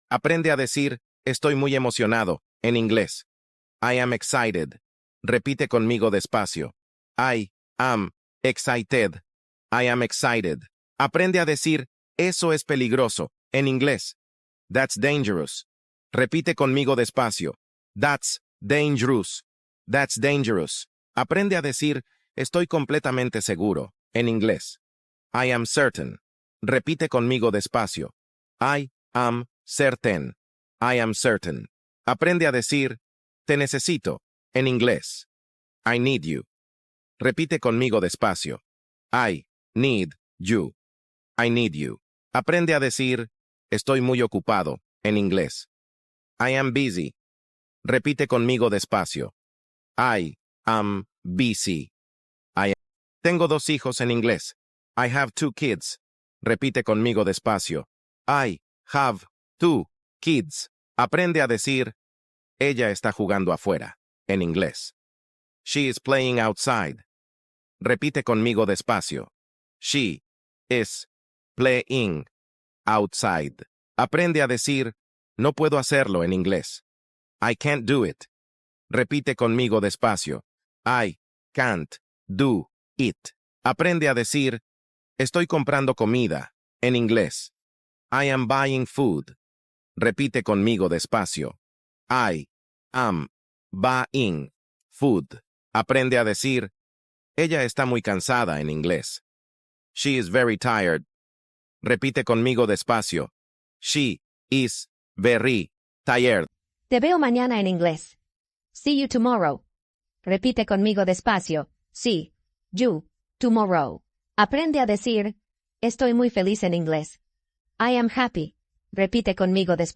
Práctica básica de inglés para principiantes, fácil y lenta